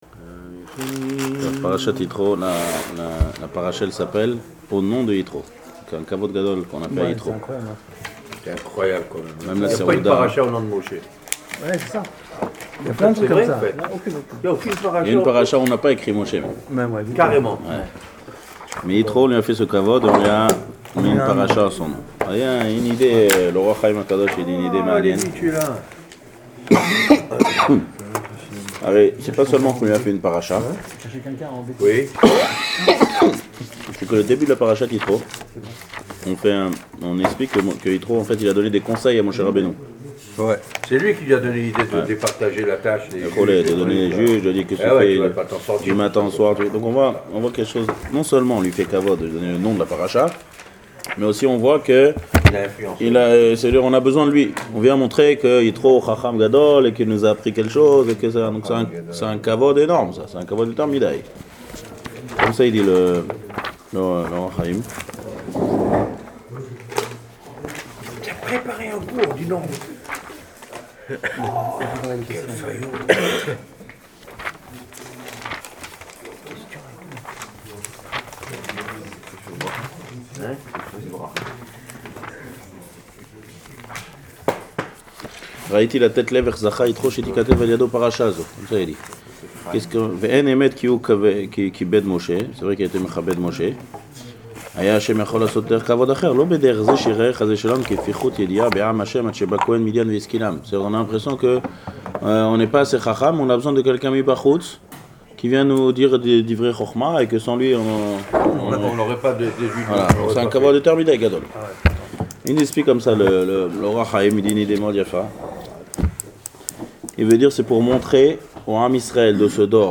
Cours sur la Parashath Ytro donné le jeudi 5 Février 2015 à Raanana.
Cours audio de 57 minutes environ.